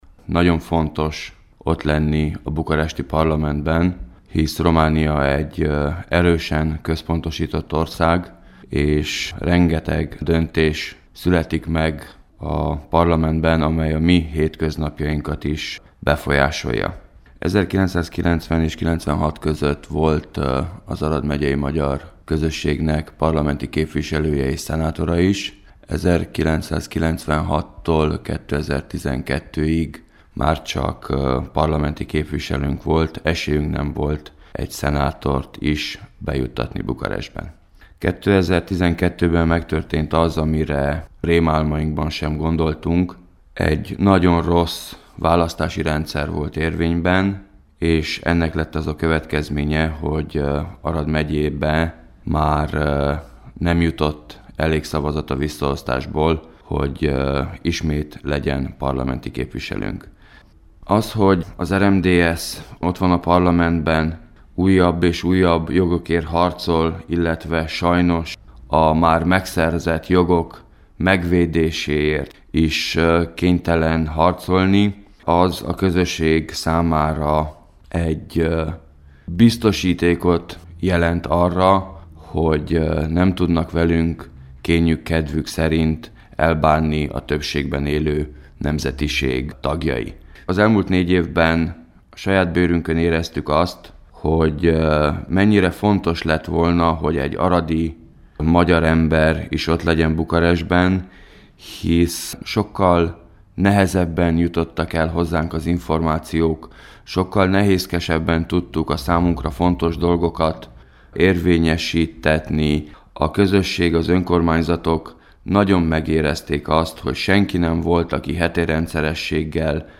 kérdezte a Kossuth Rádió Határok nélkül című műsorában